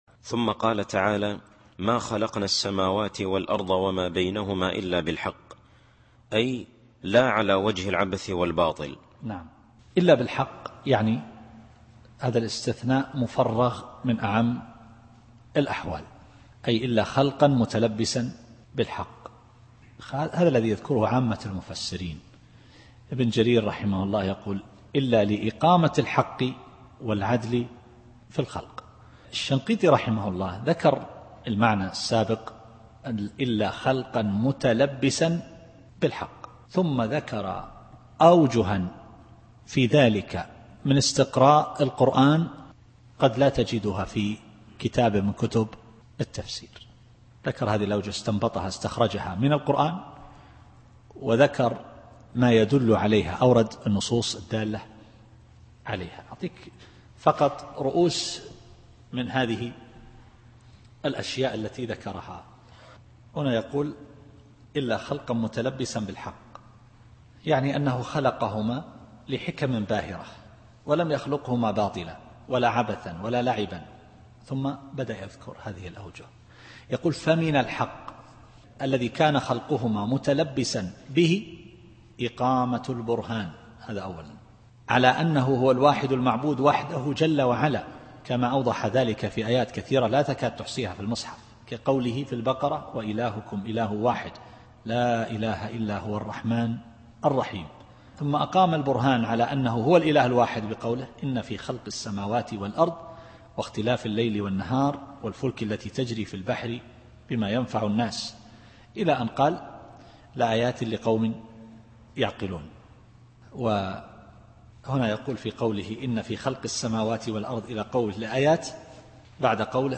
التفسير الصوتي [الأحقاف / 3]